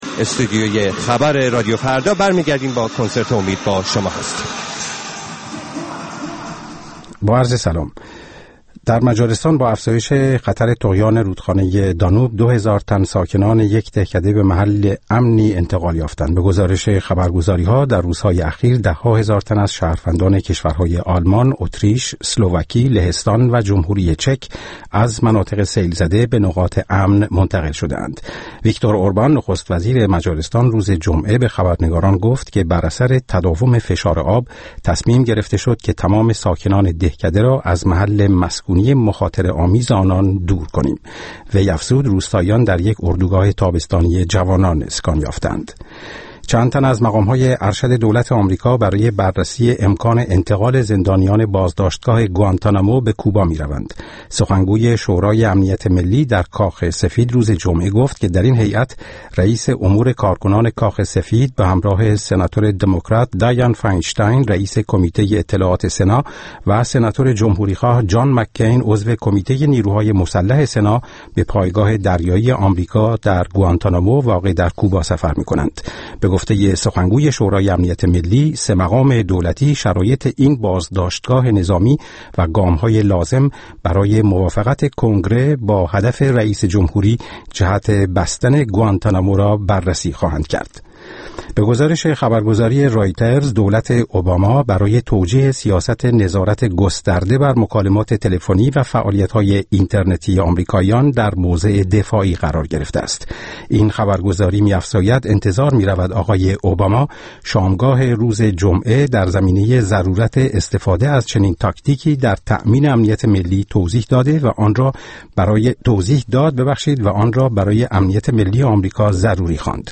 کنسرت «اميد برای ايران» با رادیو فردا